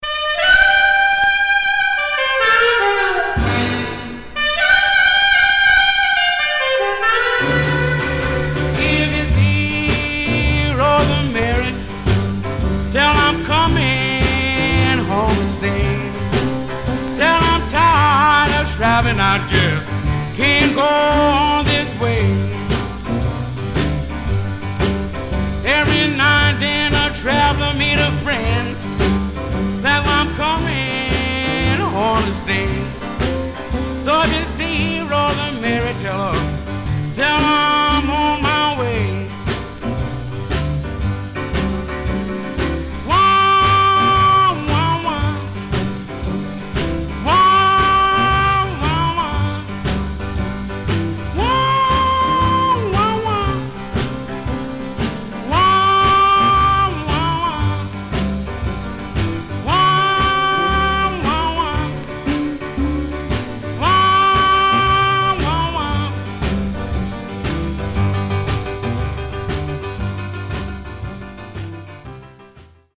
Stereo/mono Mono
Rythm and Blues